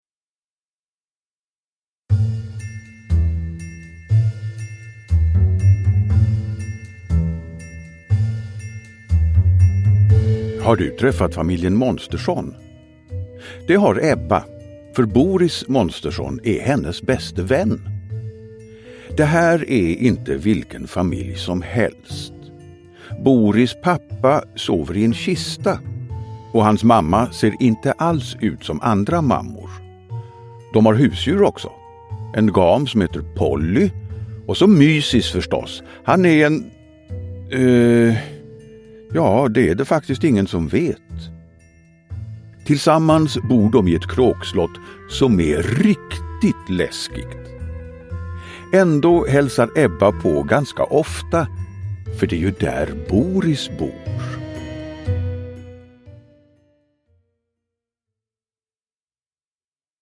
Ett monster i vildmarken – Ljudbok – Laddas ner